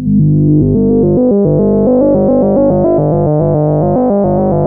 JUP 8 G2 11.wav